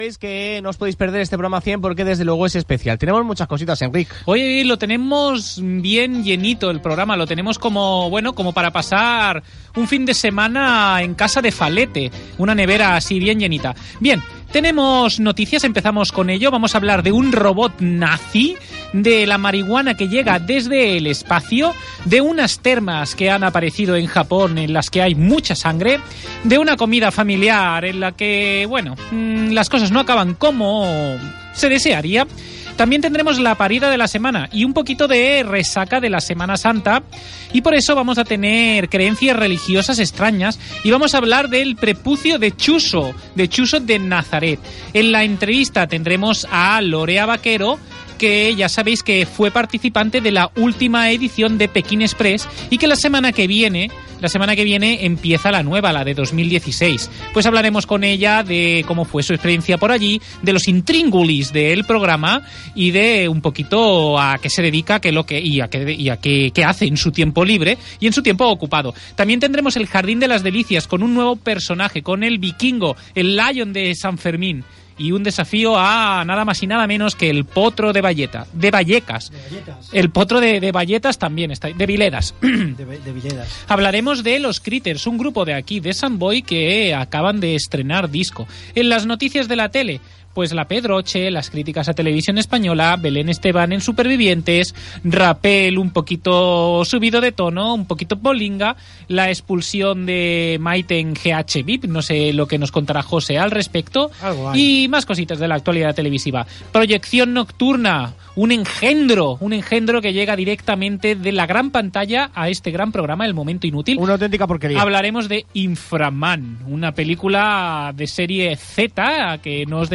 Espai número 100, presentació, sumari de continguts, plataformes on es pot escoltar el programa, resmun informatiu de notícies inútils Gènere radiofònic Entreteniment